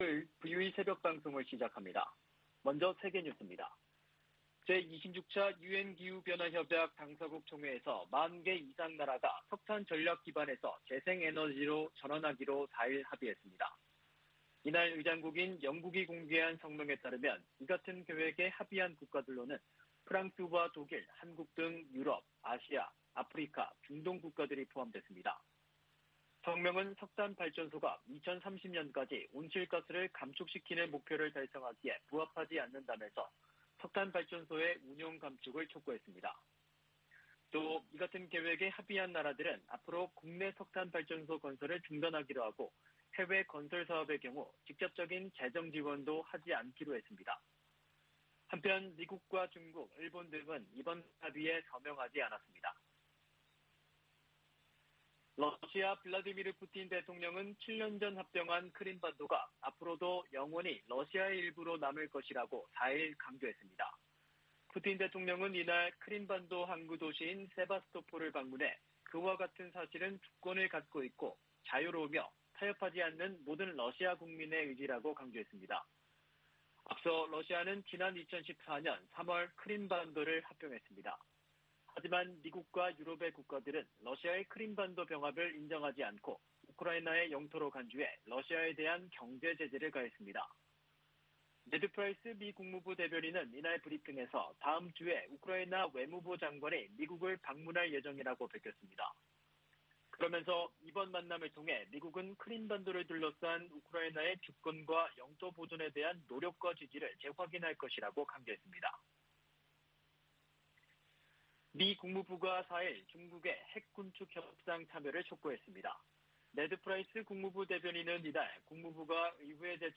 VOA 한국어 '출발 뉴스 쇼', 2021년 11월 6일 방송입니다. 북한의 불법 무기 프로그램 개발을 막기 위해 전 세계가 유엔 안보리 대북제재를 이행할 것을 미 국무부가 촉구했습니다.